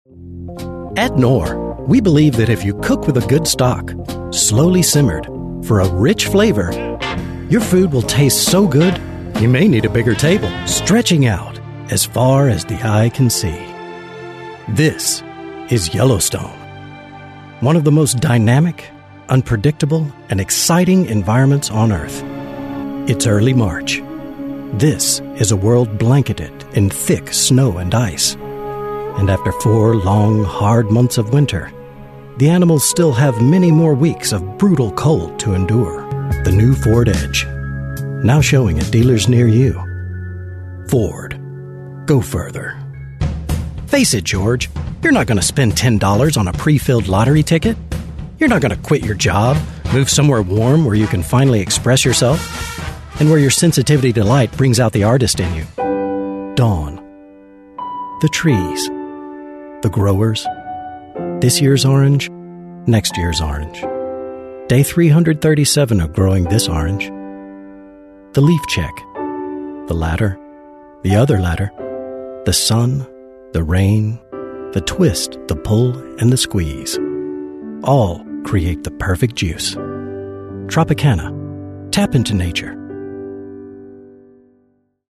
His rich, warm voice conveys trustworthiness and sincerity but with a sense of fun. But he also enjoys character work which encompasses every personality and emotion.
Male / 40s, 50s / American, English / American
Showreel